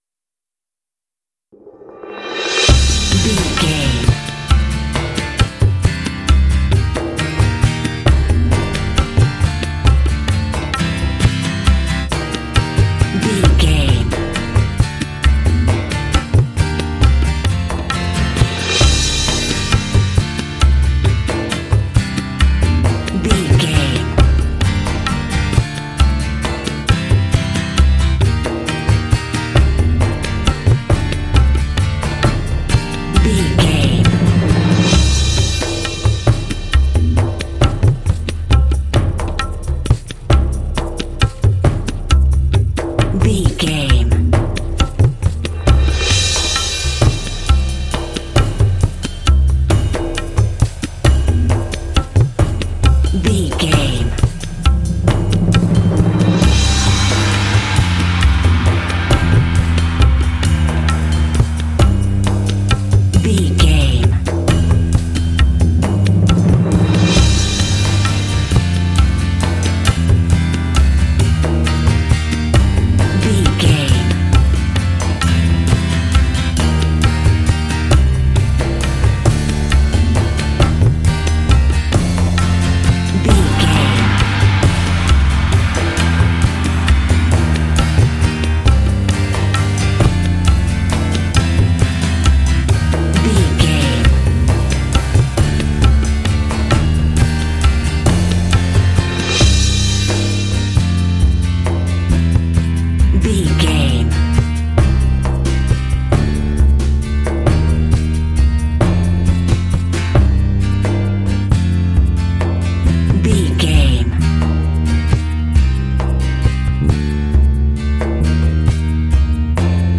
Uplifting
Aeolian/Minor
Slow
mystical
dreamy
peaceful
acoustic guitar
percussion
drums
bass guitar
cinematic